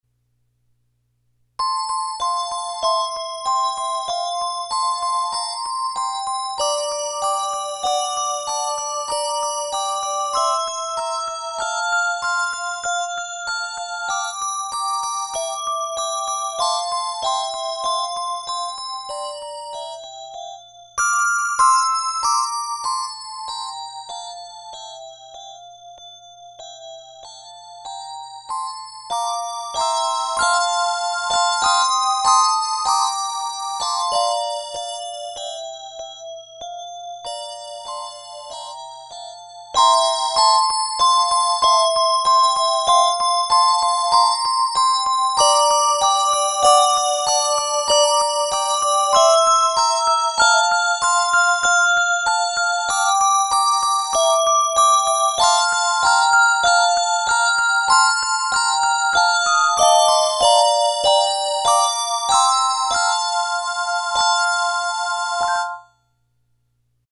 has a variety of dynamic levels and ends with a martellato